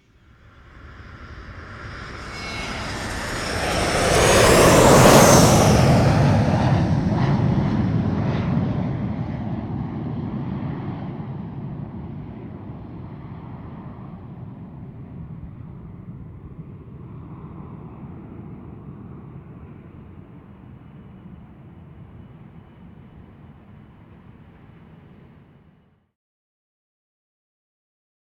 jetgoing.ogg